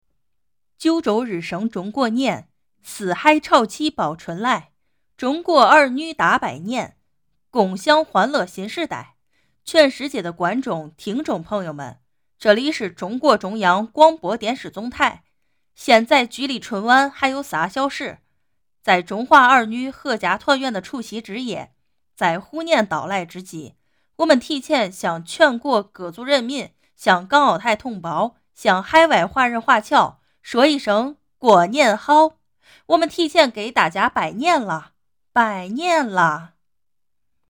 活力大气-春晚主持